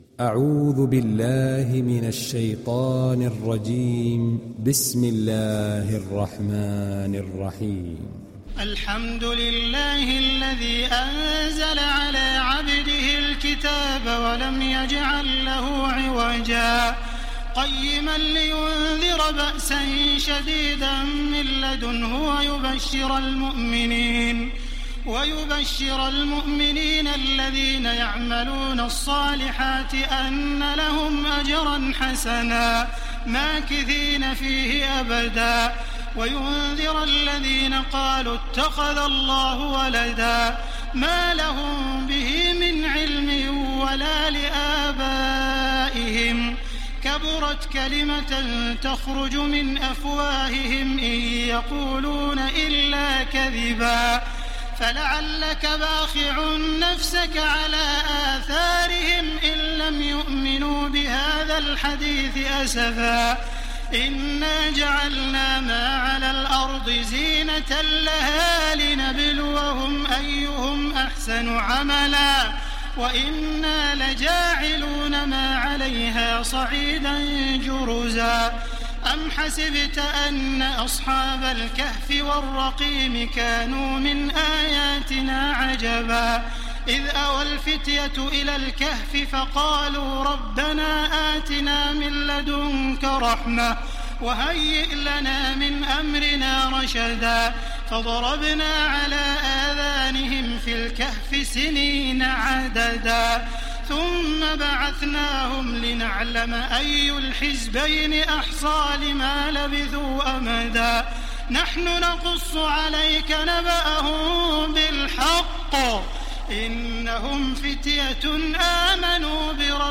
Sourate Al Kahf Télécharger mp3 Taraweeh Makkah 1430 Riwayat Hafs an Assim, Téléchargez le Coran et écoutez les liens directs complets mp3
Télécharger Sourate Al Kahf Taraweeh Makkah 1430